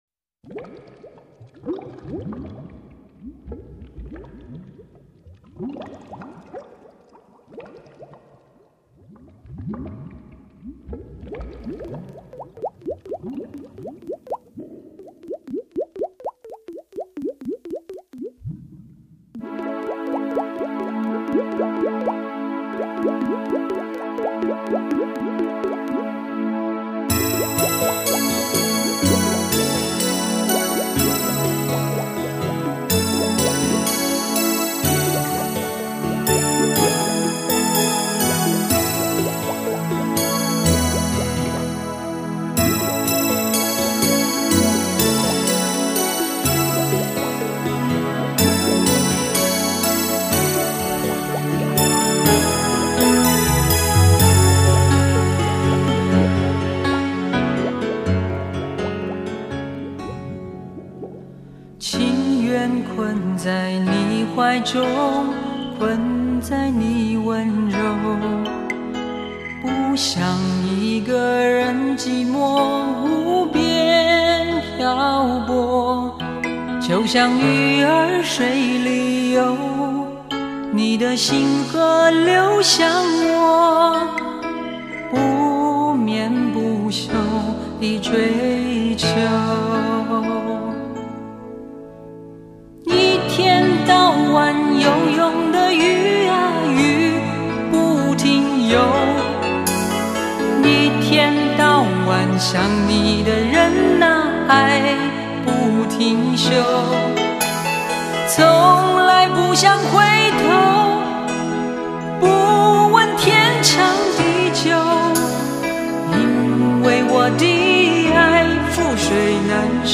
唱片类型：流行经典